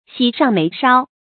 喜上眉梢 注音： ㄒㄧˇ ㄕㄤˋ ㄇㄟˊ ㄕㄠ 讀音讀法： 意思解釋： 眉梢：眉尖。